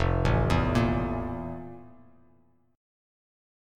FM11 Chord
Listen to FM11 strummed